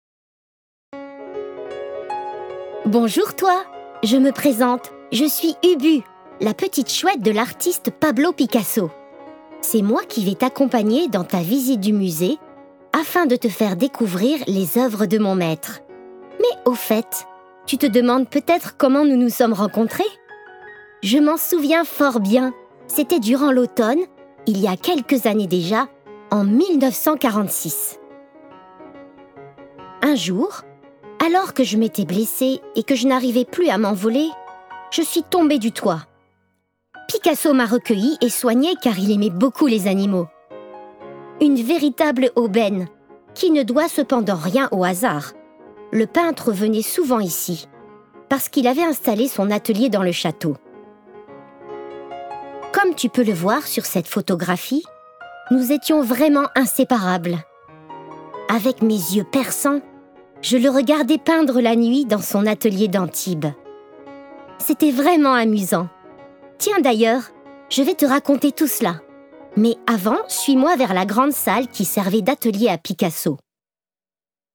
Audio guide Musée Picasso
J'ai une voix jeune, légère, cristalline et pétillante...